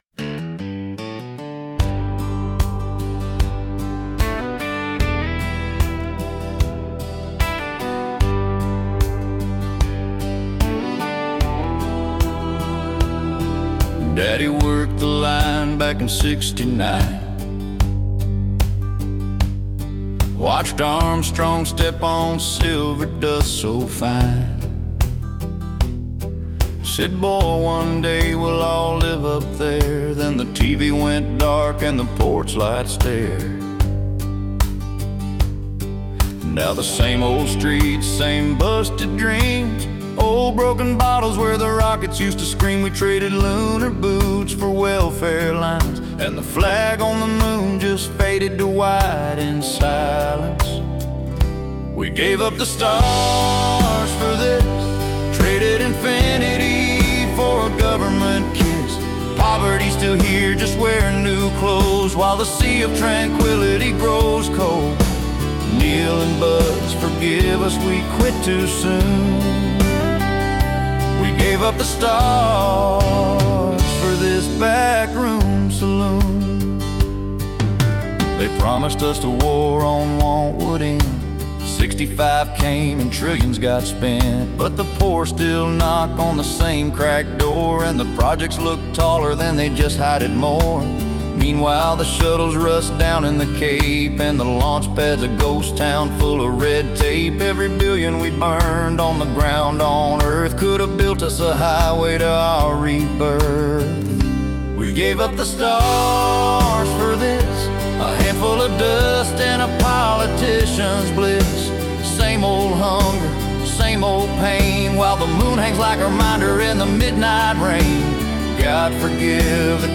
My favorite country song is The Highwayman.